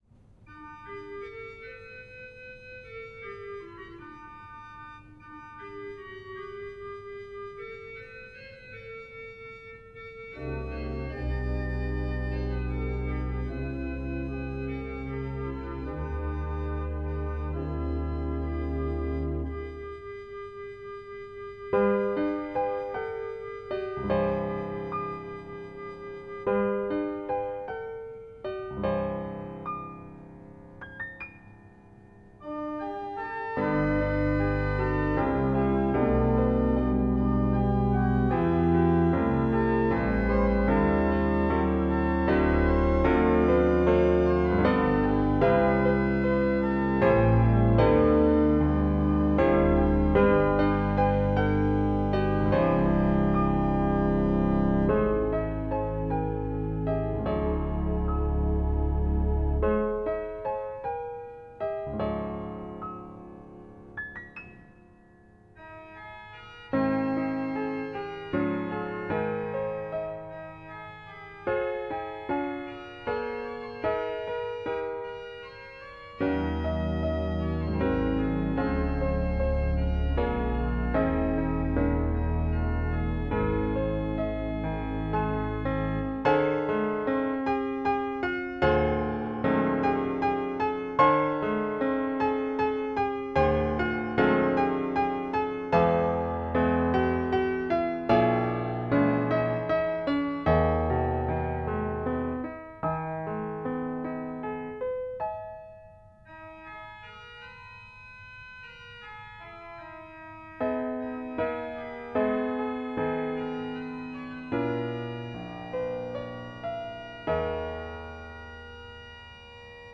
Voicing: Piano and Organ